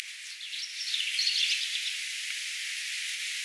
mika_laji_ehdotan_punarintaa.mp3